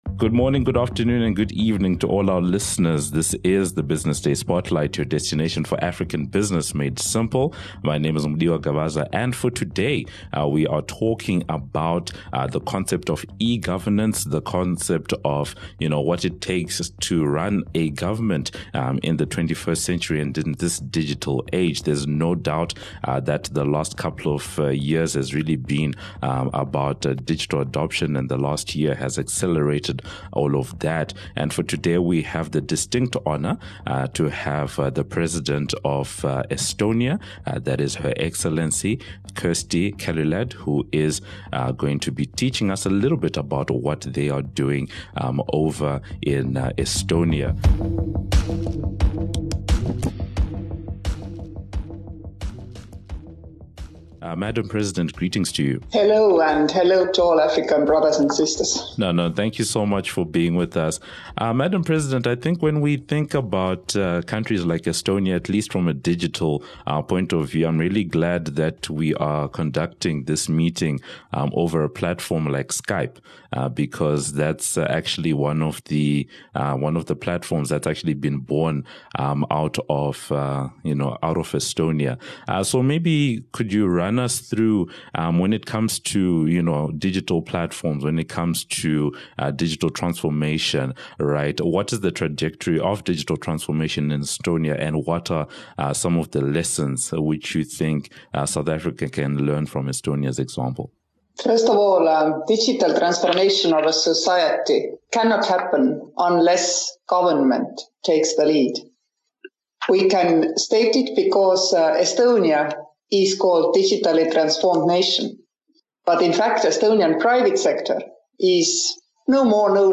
is joined by the president of the Republic of Estonia, Her Excellency, Kersti Kaljulaid. The discussion focuses on the trajectory of digital transformation in Estonia and what are the lessons which South Africa can learn; how has Estonia ensured digital resilience during the Covid-19 crisis; the concept of e-Residency and way is it assisting entrepreneurs and freelancers to operate businesses in the European Union; how the Estonian government has managed to keep its systems running during the pandemic; what made Estonia decide on giving e-residency to people around the world initially; and some of the measures that Estonia has taken during this time to cope with the pandemic.